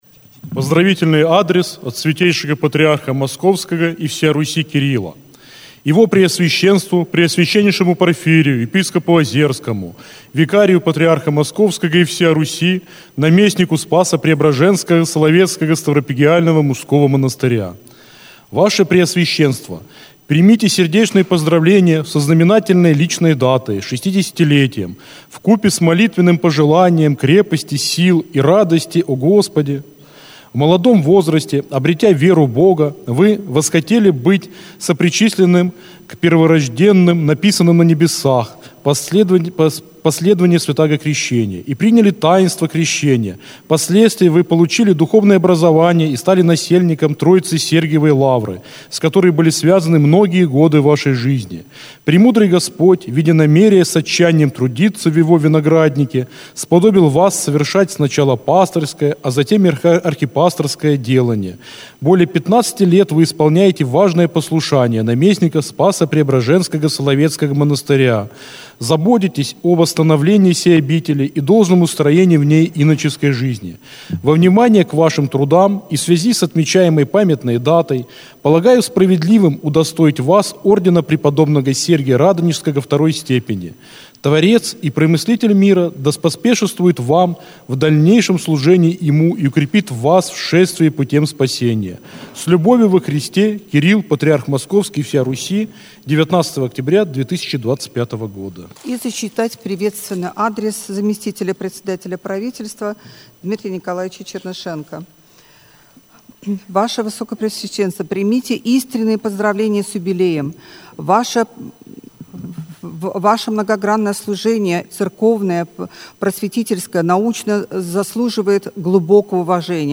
В Неделю 19-ю по Пятидесятнице и в день своего 60-летия, 19 октября 2025 года, Преосвященный Порфирий Епископ Озерский, наместник Спасо-Преображенского Соловецкого ставропигиального мужского монастыря возглавил Литургию на Соловецком подворье в Москве в храме великомученика Георгия Победоносца в Ендове.
По окончании богослужения было зачитано Патриаршее поздравление епископу Порфирию в связи с 60-летием со дня рождения: